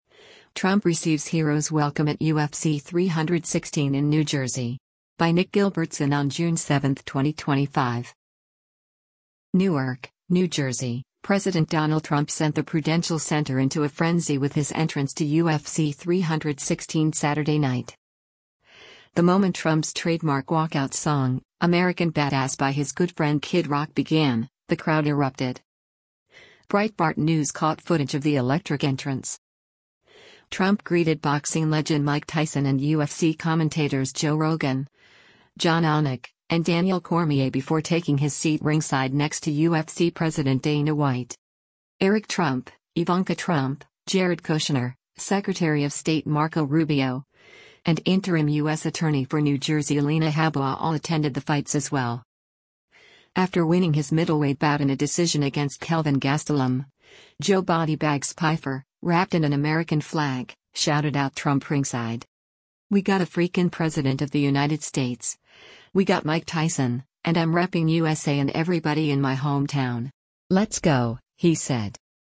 NEWARK, New Jersey—President Donald Trump sent the Prudential Center into a frenzy with his entrance to UFC 316 Saturday night.
The moment Trump’s trademark walkout song, “American Badass” by his good friend Kid Rock began, the crowd erupted.